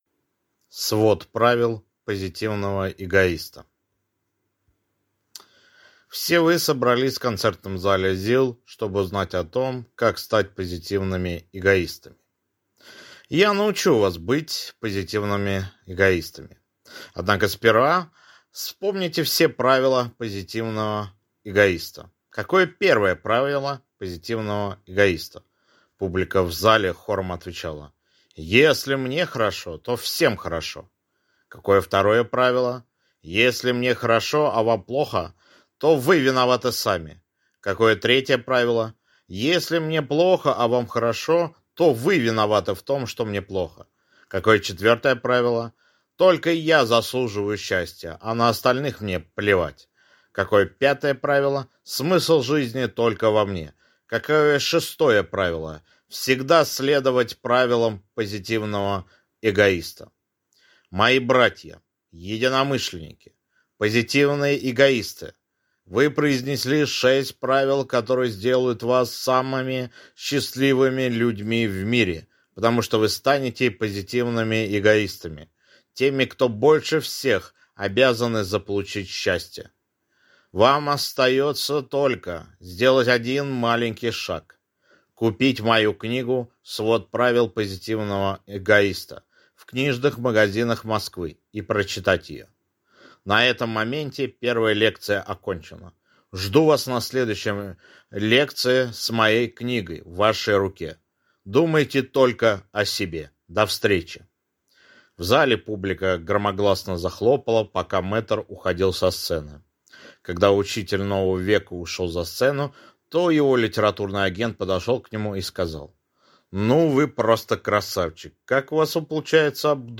Аудиокнига Свод правил позитивного эгоиста | Библиотека аудиокниг